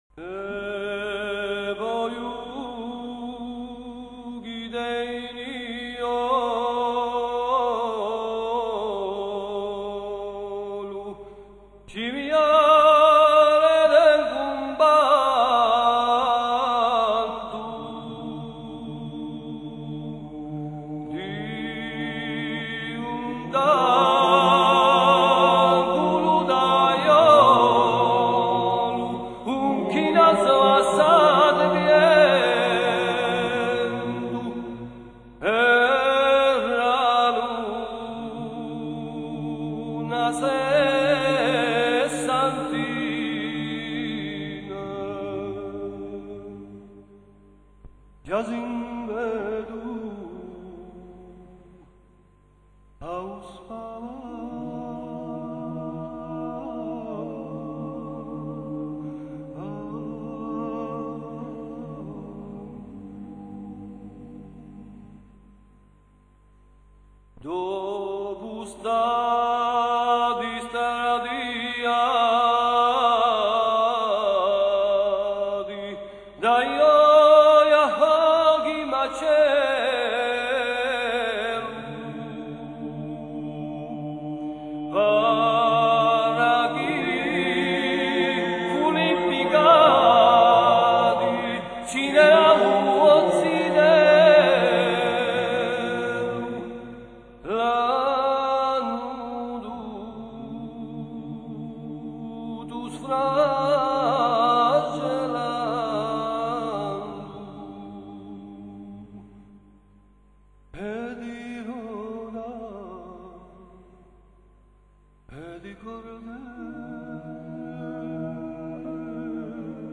A popular ballad.